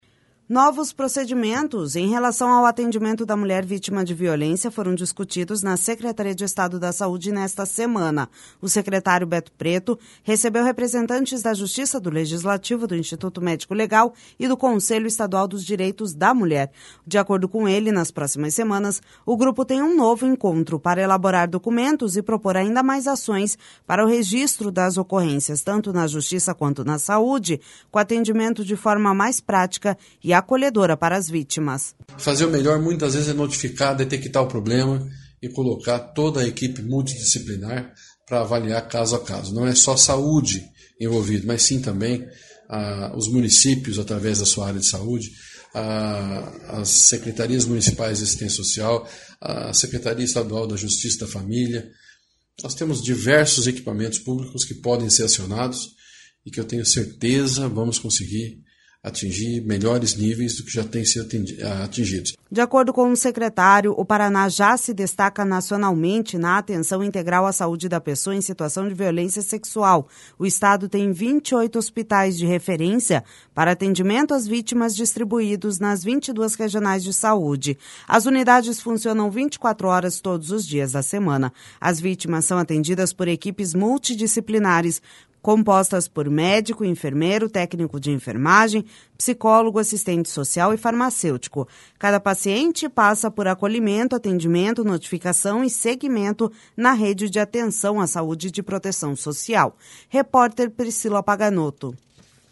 De acordo com ele, nas próximas semanas, o grupo tem um novo encontro para elaborar documentos e propor ainda mais ações para o registro das ocorrências, tanto na Justiça quanto na saúde, com atendimentos de forma mais prática e acolhedora para as vítimas.// SONORA BETO PRETO//De acordo com o secretário, o Paraná já se destaca nacionalmente na atenção integral à saúde da pessoa em situação de violência sexual.